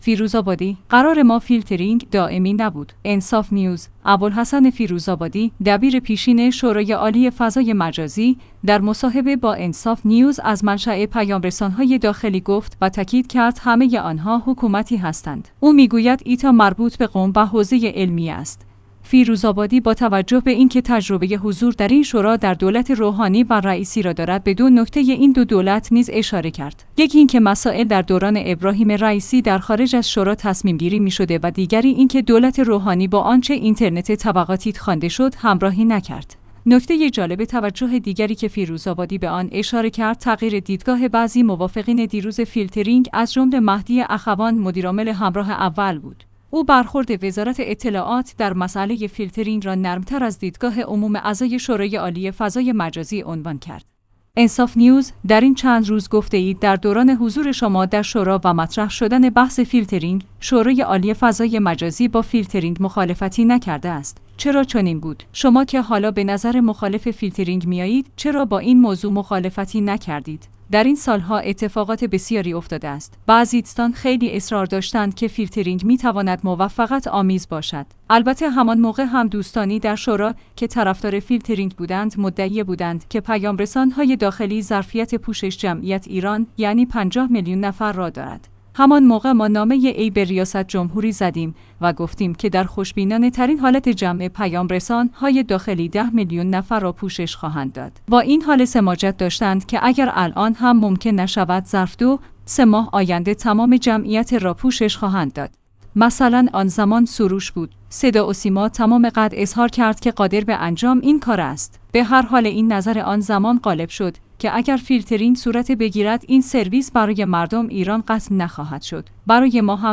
انصاف نیوز/ابوالحسن فیروزآبادی، دبیر پیشین شورایعالی فضای مجازی، در مصاحبه با انصاف نیوز از منشأ پیام‌رسان‌های داخلی گفت و تاکید کرد همه‌ی آنها حکومتی هستند. او می‌گوید ایتا مربوط به قم و حوزه‌ی علمیه است.